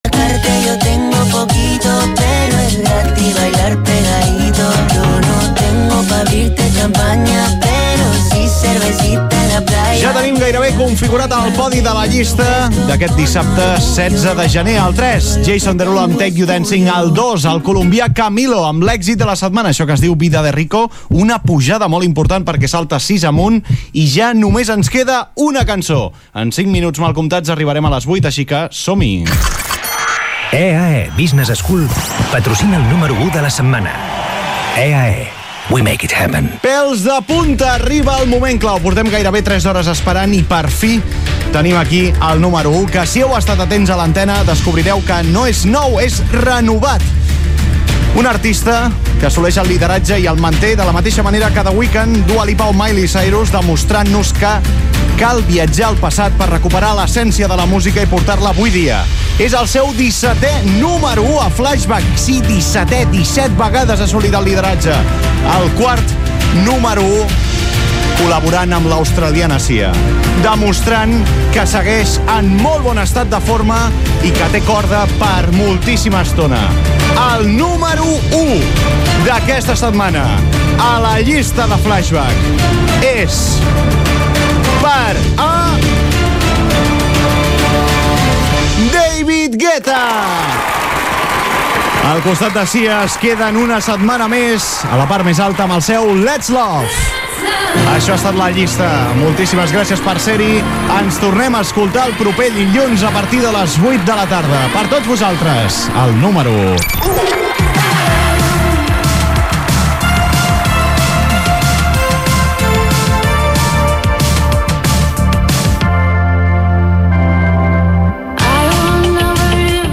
Tema musical, publicitat, número 1 de la llista i comiat del programa.
Musical